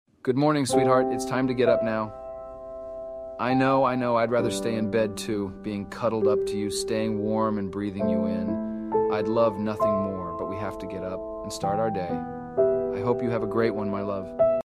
A Seb alarm sound